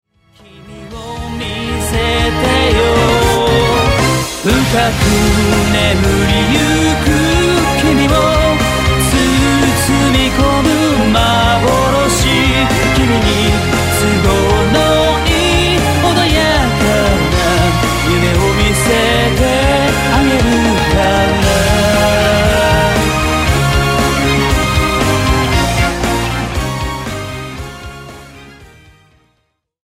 キャラクターイメージソング